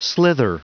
Prononciation du mot slither en anglais (fichier audio)
Prononciation du mot : slither